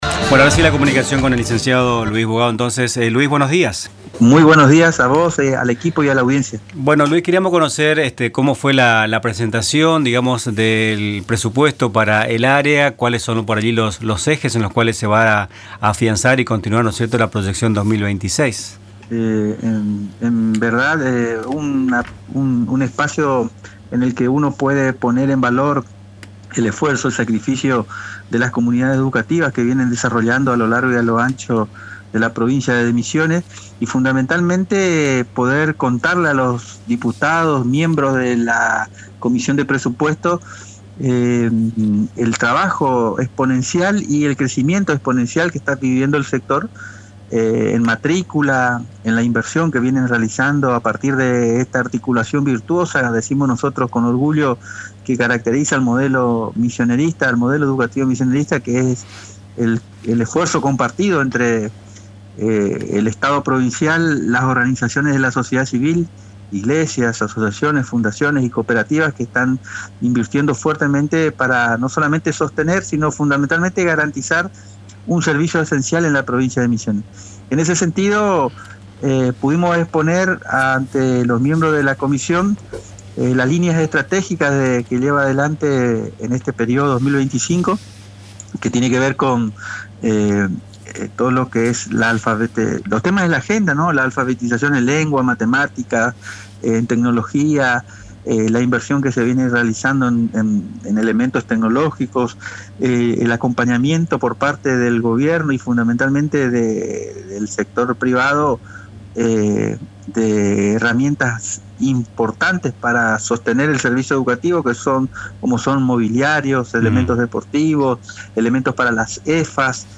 El director ejecutivo del Servicio Provincial de Enseñanza Privada de Misiones (SPEPM), Lic. Luis Bogado, dialogó con Nuestras Mañanas sobre la reciente presentación del presupuesto del área ante la comisión de Presupuesto de la Legislatura provincial y los principales ejes de la proyección para 2026.